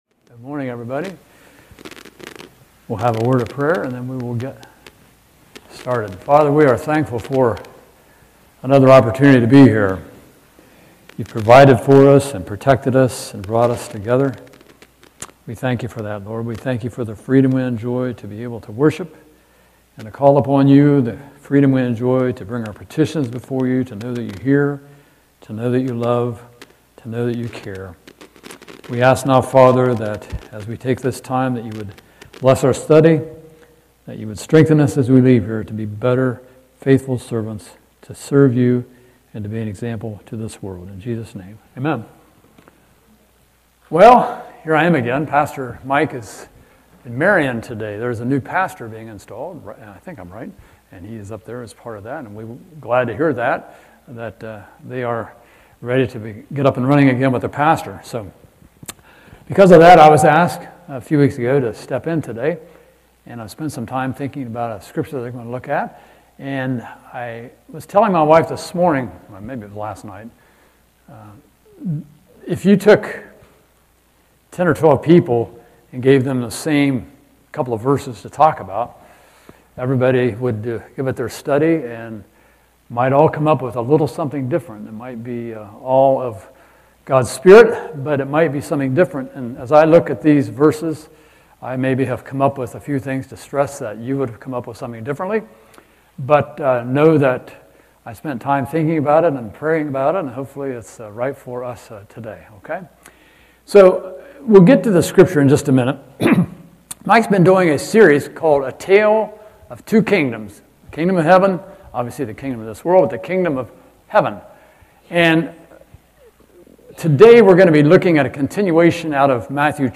In this sermon series, we will explore Jesus’ Kingdom parables to learn what this new citizenship means for our faith, our loyalties, and our daily lives, and how Christ sends us into the world to bear witness to His Kingdom—freeing us from the idols, false promises, and misplaced hopes of the kingdom of man.